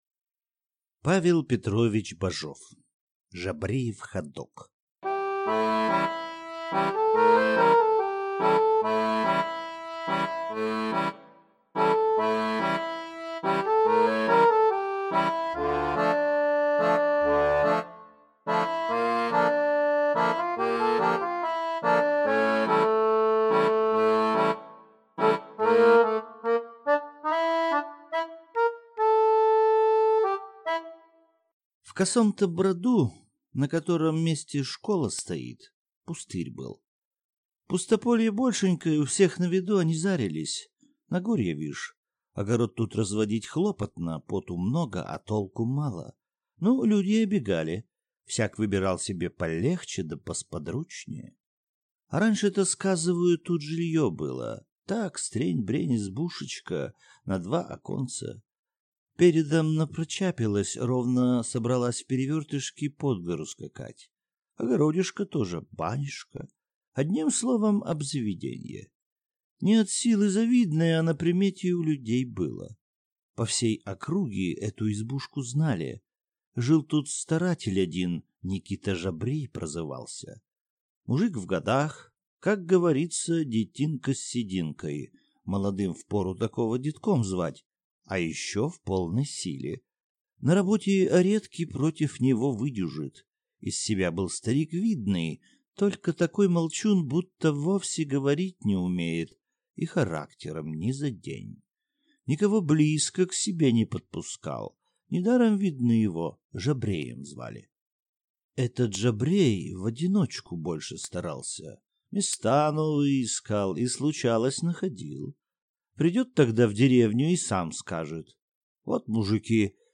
Аудиокнига Жабреев ходок | Библиотека аудиокниг